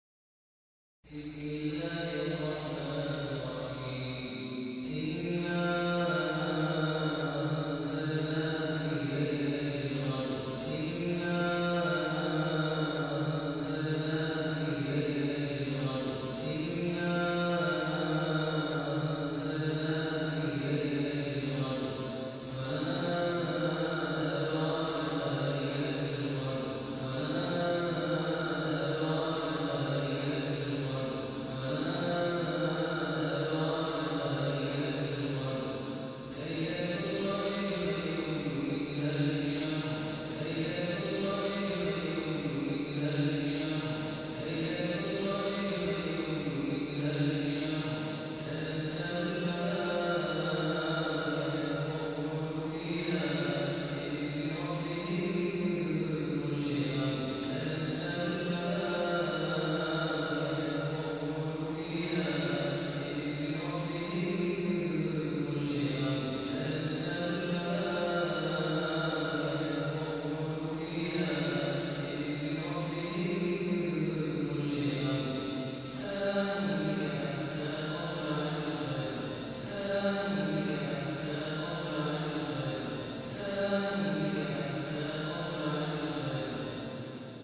The Holy Quran recitation for Famous readers to listen and download